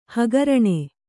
♪ hagaraṇe